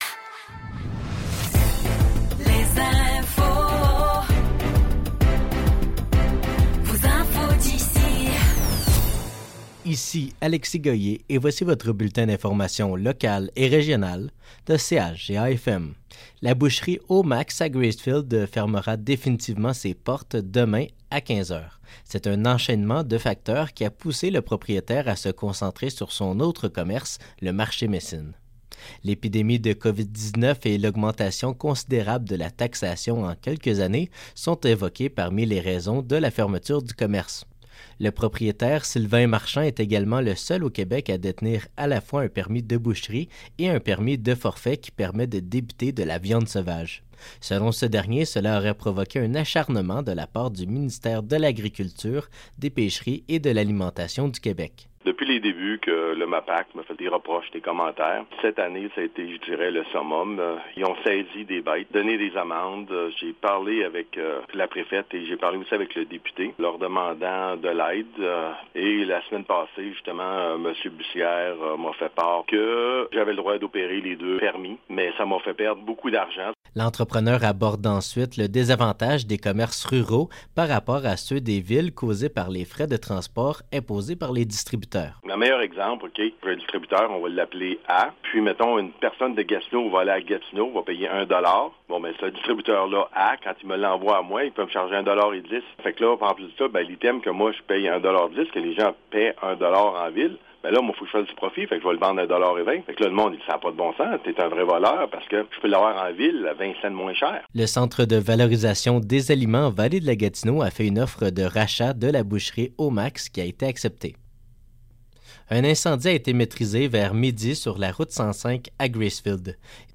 Nouvelles locales - 28 décembre 2023 - 12 h